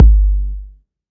Kick SwaggedOut 4.wav